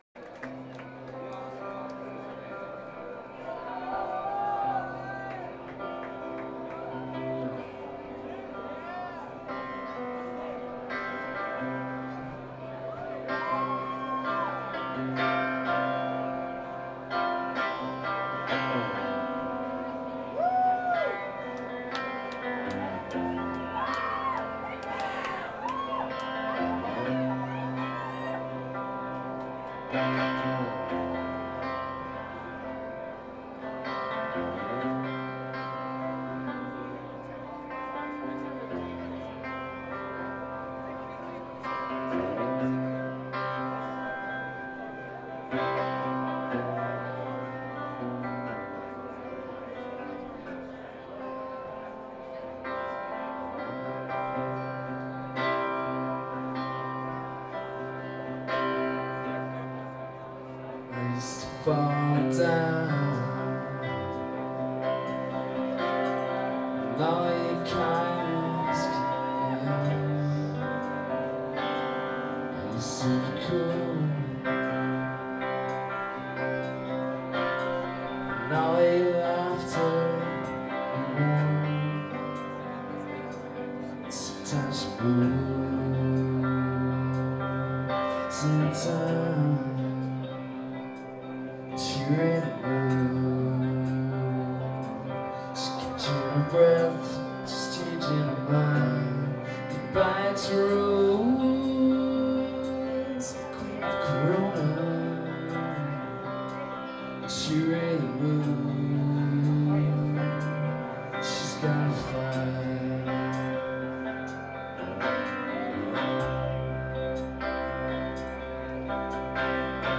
highbury garage london june 29 2000